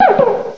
cry_not_cubchoo.aif